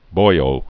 (boiō)